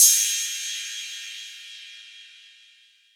Crashes & Cymbals
Cymbal 4 [ crazy ].wav